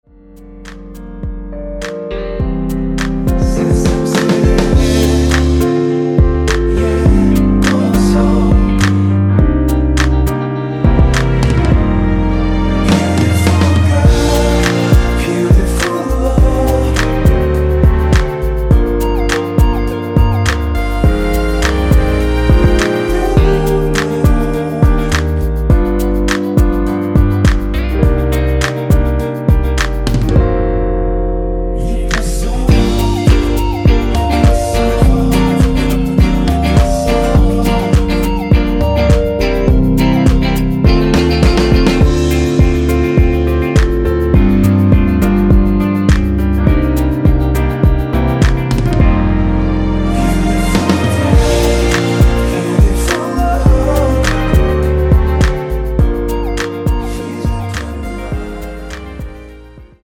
원키에서(-2)내린 코러스 포함된 MR이며 랩은 포함되지 않습니다.(미리듣기 참조)
Bb
앞부분30초, 뒷부분30초씩 편집해서 올려 드리고 있습니다.
중간에 음이 끈어지고 다시 나오는 이유는